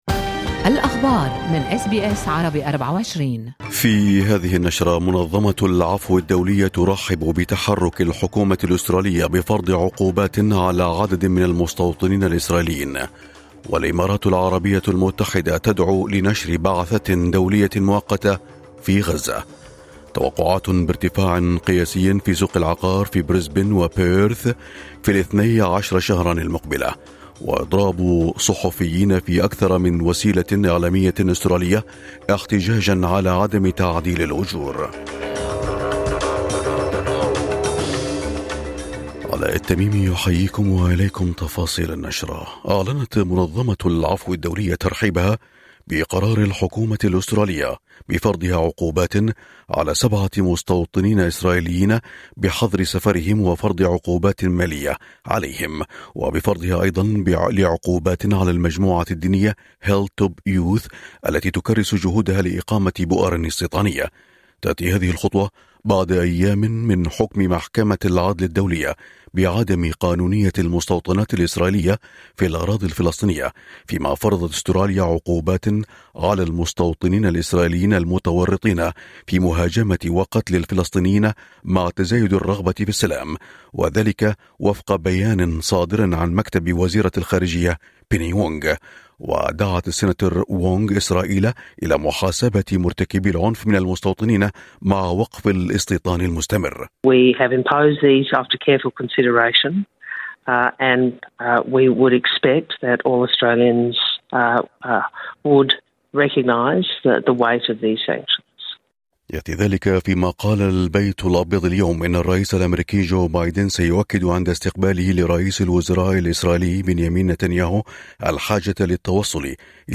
نشرة أخبار الصباح 26/7/2024